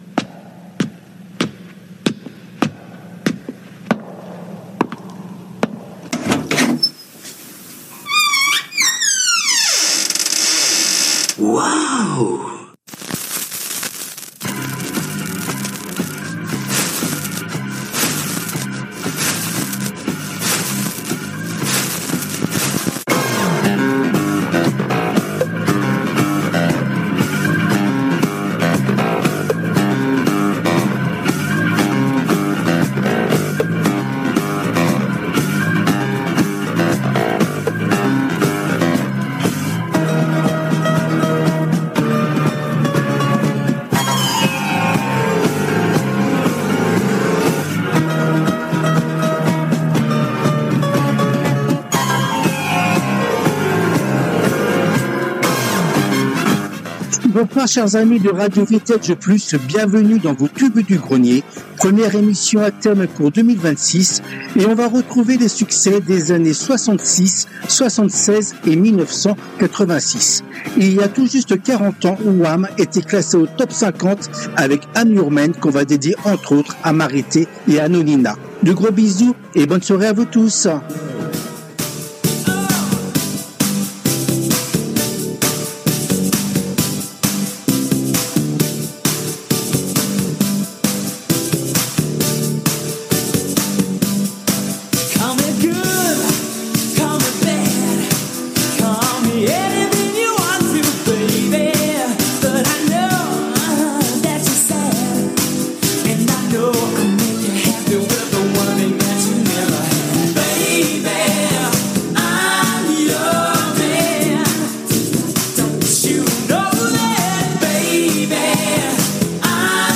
Cette 144ème édition a été diffusée en direct le mardi 13 janvier 2026 à 19h depuis les studios de RADIO RV+ à PARIS .